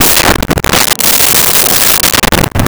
Glass On Bar With Slide 03
Glass On Bar With Slide 03.wav